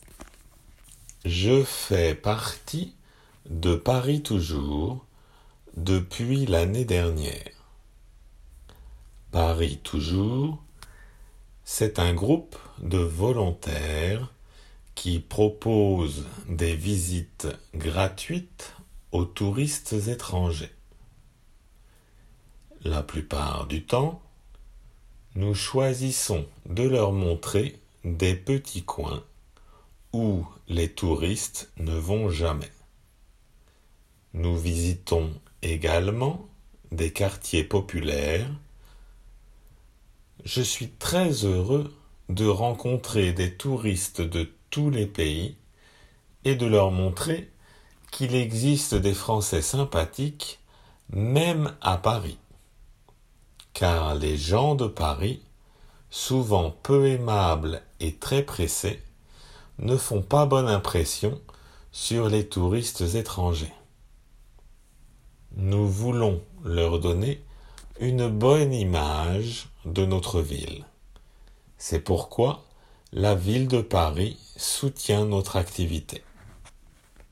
読まれる文